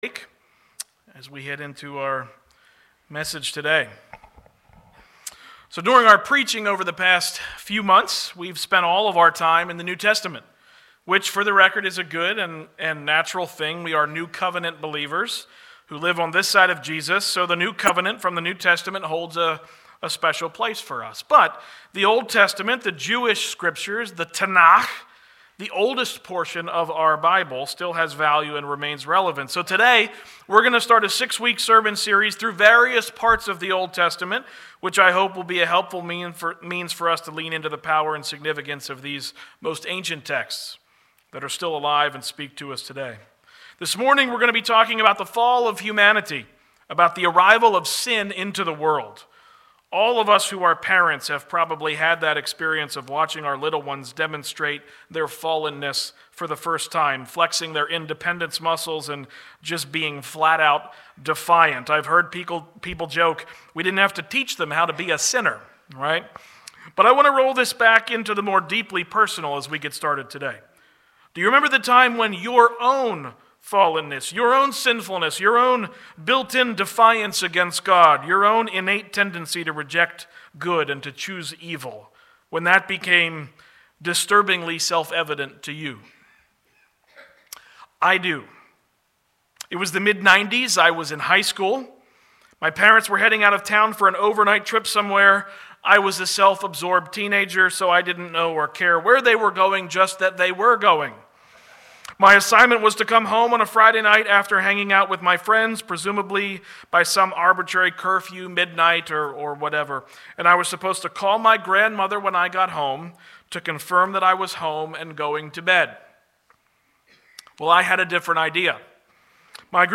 Service Type: Sunday Morning Services